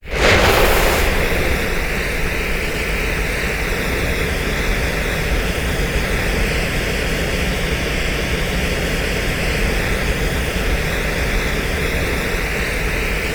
ignite_trail.wav